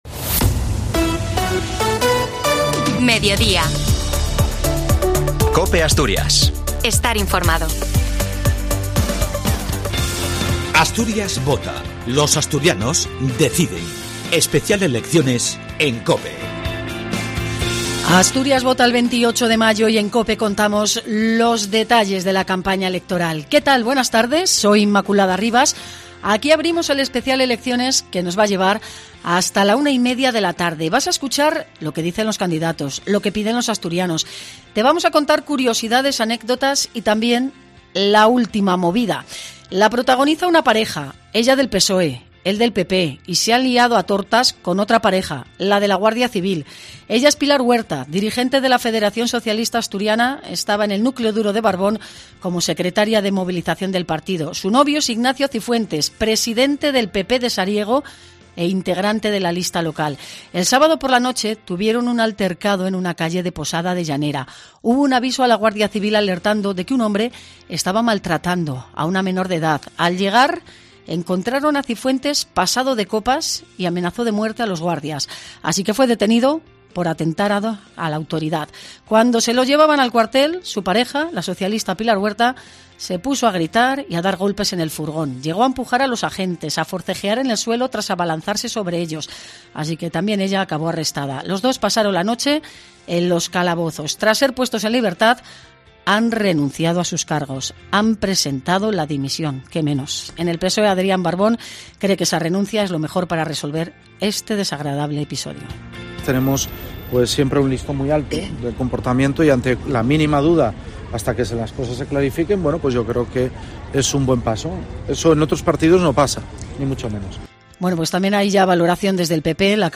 Toda la información electoral la tienes en COPE Asturias: quiénes son los candidatos a las elecciones autonómicas, qué propuestas tienen, curiosidades, anécdotas y, también, la voz de los asturianos. Escucha qué piden los ciudadanos al próximo presidente del Principado y qué demandan nuestros famosos al próximo Gobierno del Principado.